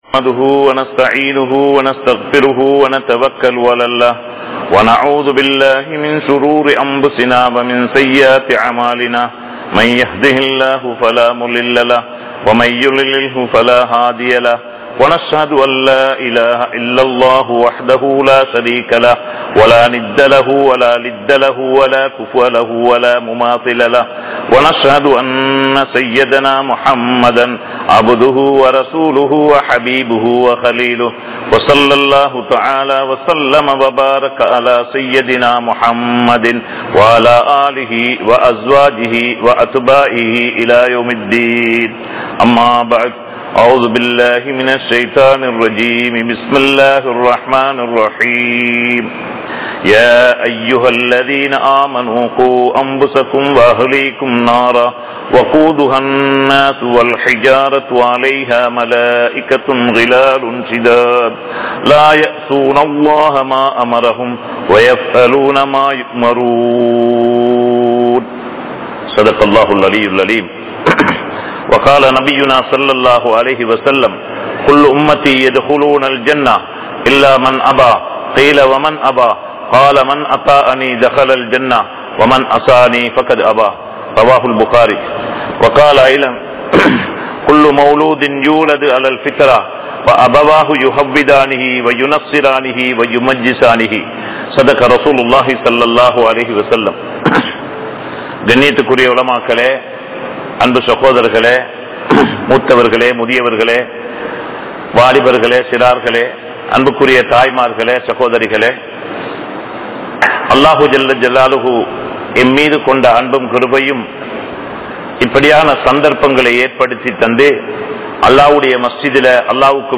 Kulanthai Valarpu (குழந்தை வளர்ப்பு) | Audio Bayans | All Ceylon Muslim Youth Community | Addalaichenai
Grand Jumua Masjitth